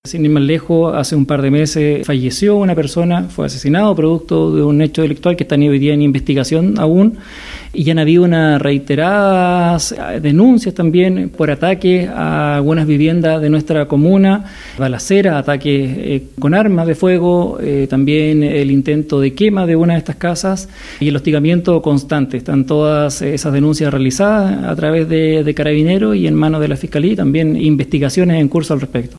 El alcalde de Mafil, Andrés Lara, aseguró que históricamente su comuna se ha caracterizado por ser muy tranquila.